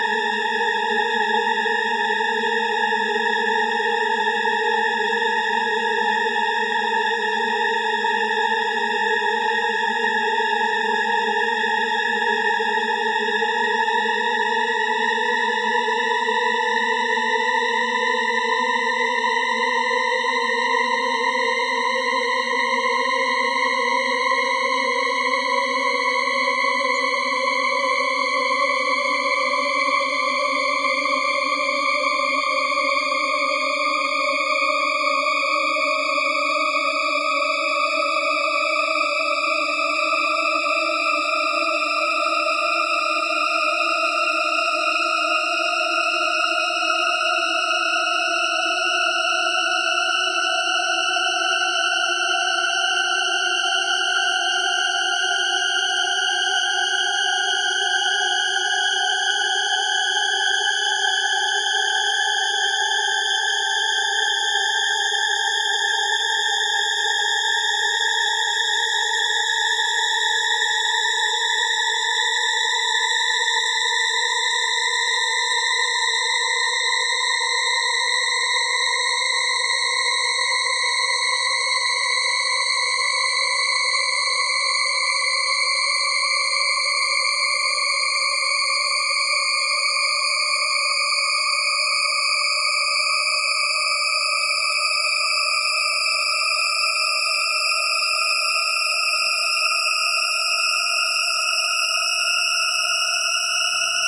描述：在Audacity中给出滑动时间刻度/俯仰偏移处理的“玻璃构建”。
标签： 出现 积聚 方法 恐惧 构建 上升 介绍 玻璃 电影 上升 紧张 反向 向后渐
声道立体声